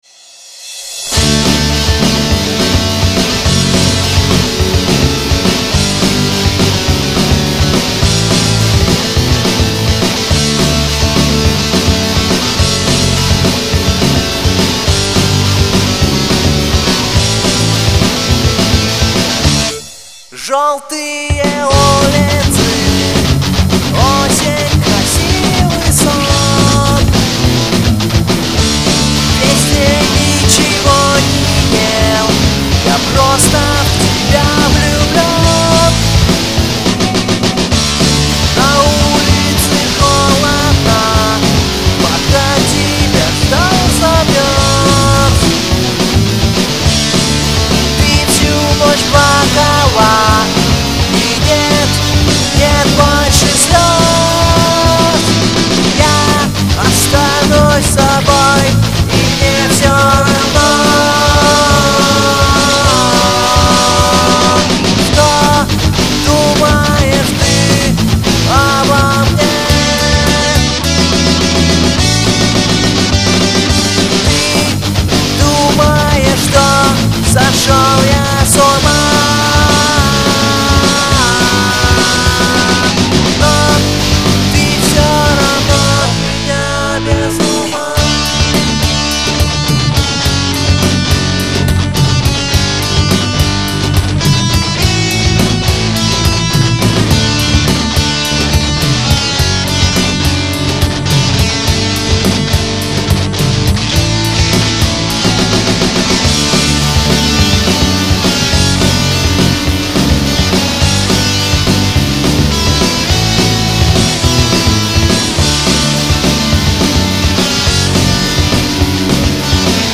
панк-рок группы